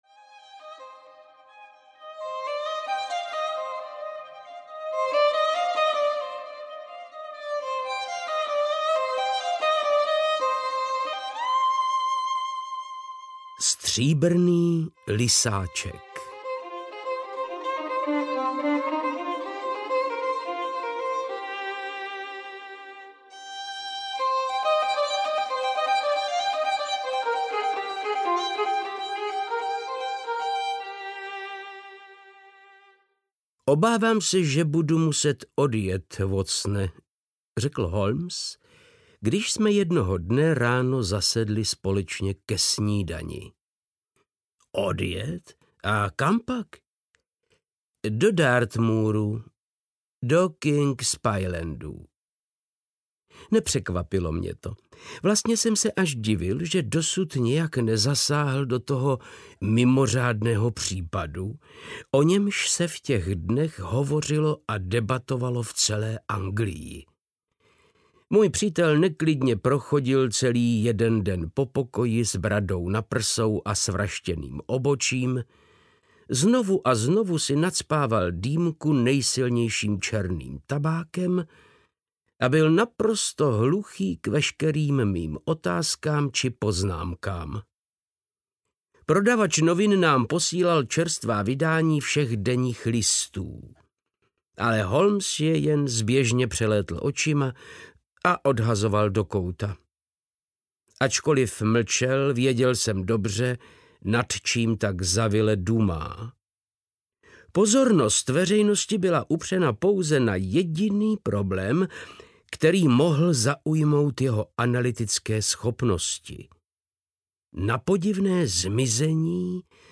Audio knihaVzpomínky na Sherlocka Holmese 1 - Stříbrný lysáček
Ukázka z knihy
• InterpretVáclav Knop